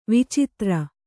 ♪ vicitra